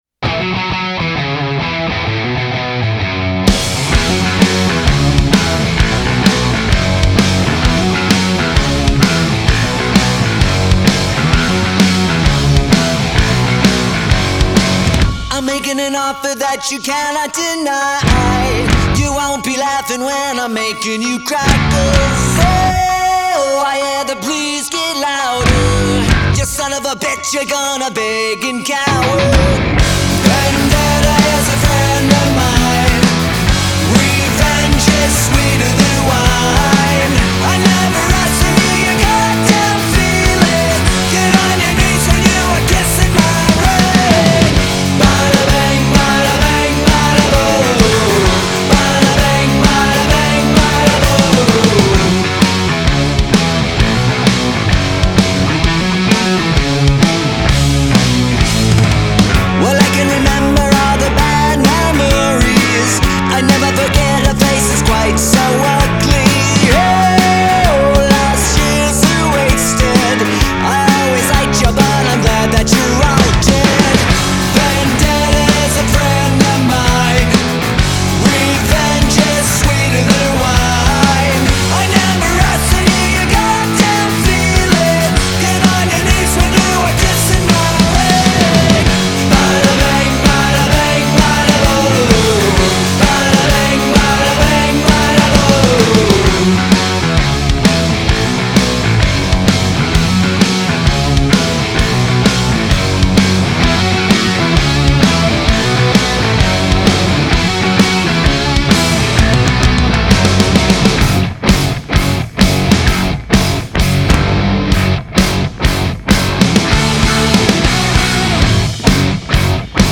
Punk Rock Alternative Rock Rock